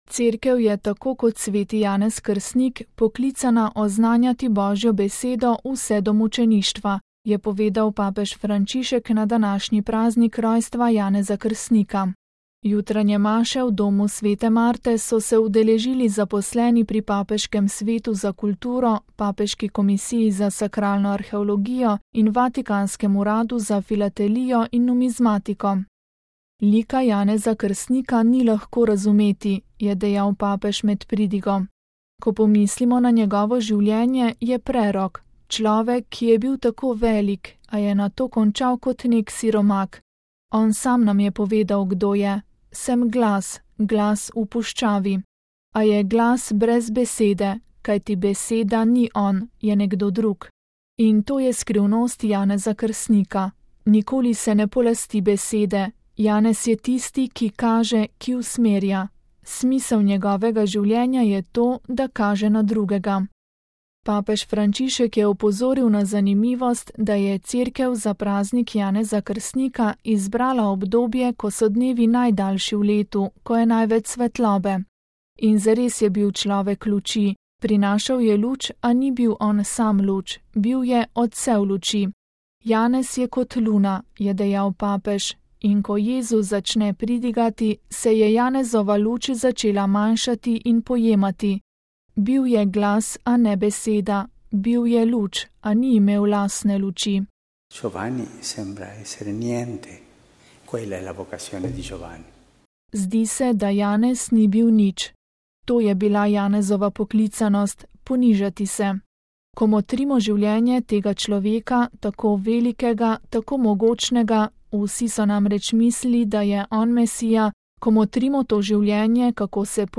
VATIKAN (ponedeljek, 24. junij 2013, RV) – Cerkev je tako kot sv. Janez Krstnik poklicana oznanjati Božjo besedo vse do mučeništva, je povedal papež Frančišek na današnji praznik rojstva Janeza Krstnika. Jutranje maše v Domu sv. Marte so se danes udeležili zaposleni pri Papeškem svetu za kulturo, Papeški komisiji za sakralno arheologijo in Vatikanskem uradu za filatelijo in numizmatiko.